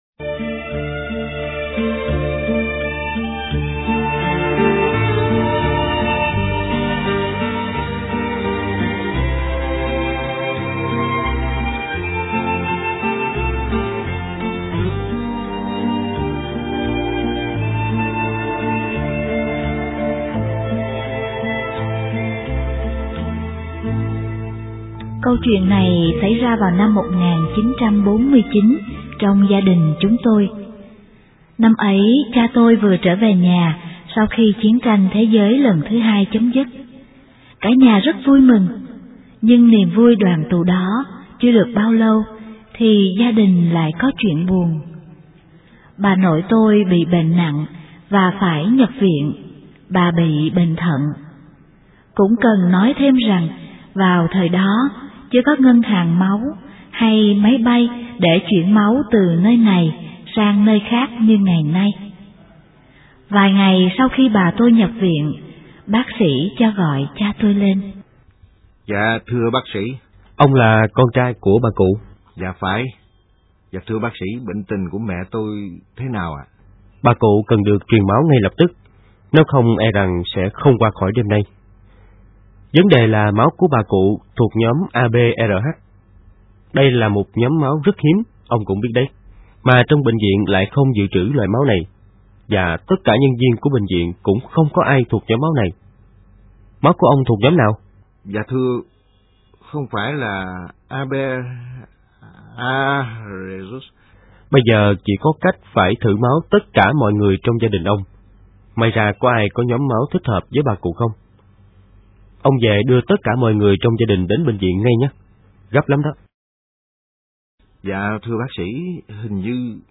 * Thể loại: Sách nói